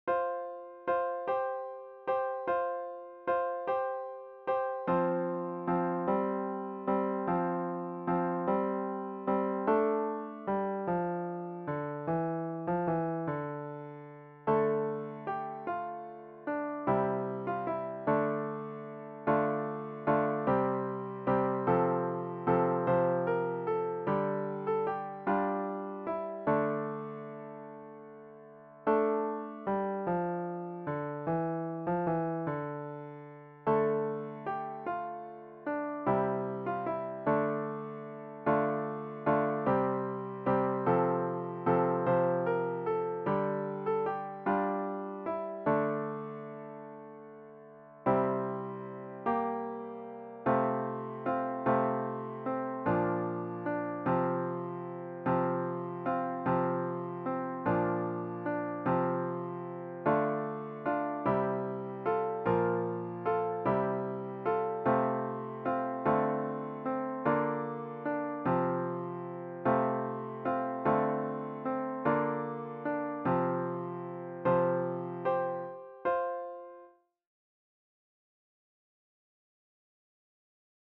Produced digitally in Finale music notation software